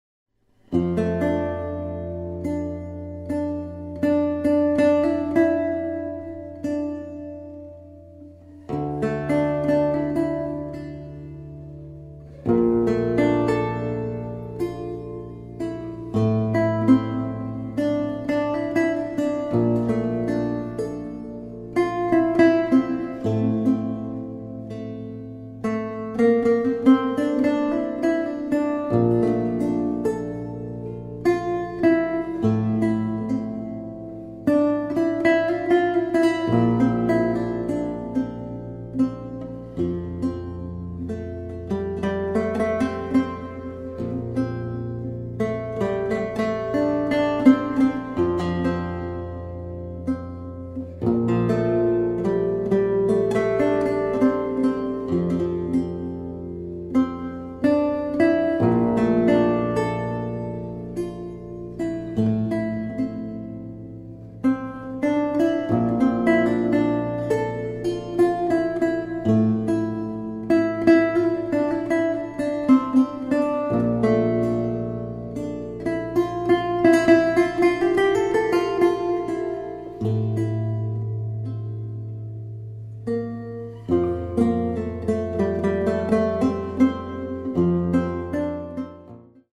composer, lute & oud player from Japan
Contemporary
, Lute , Relaxing / Meditative